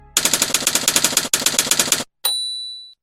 Message Alert Tones.